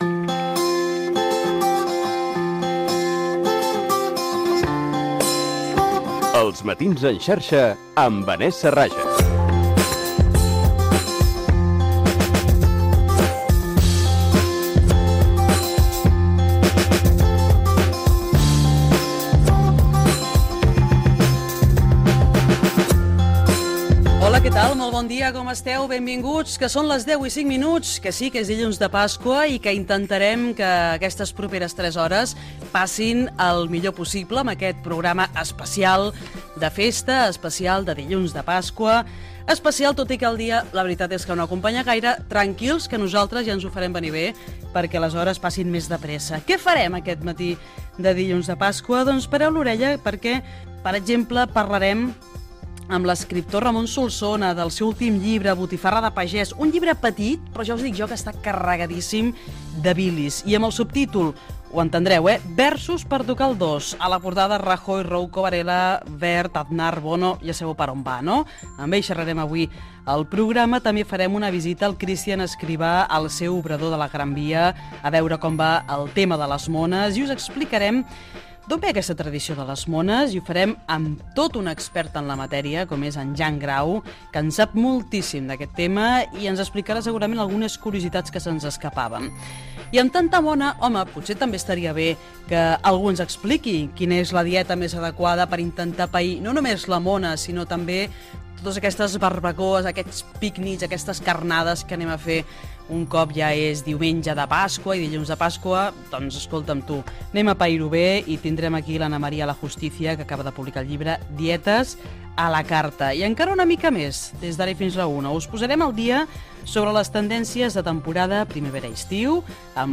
Indicatiu del programa, salutació i sumari de l'especial dilluns de Pasqua.
Entreteniment
FM